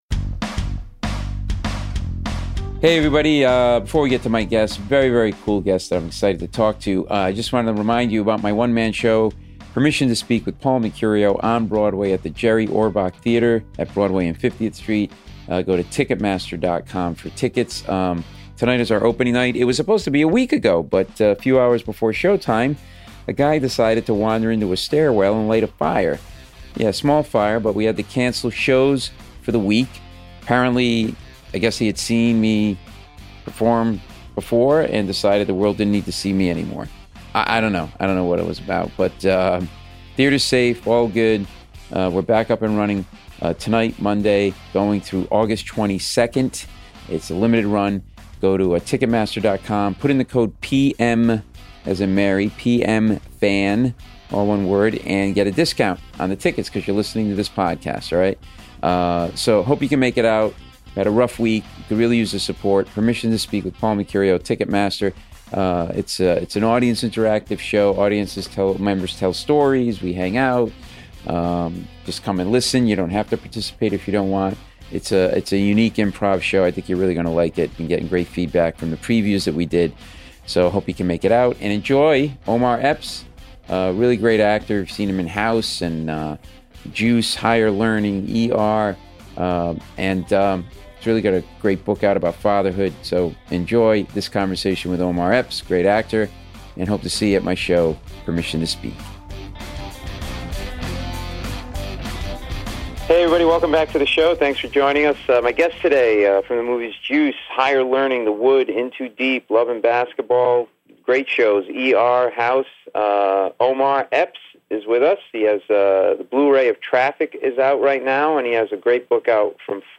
A wide-ranging conversation with Omar, an amazing actor, about his acting career, his new book about the importance of fatherhood, what it was like growing up without a father, his passion for the arts and sports and more.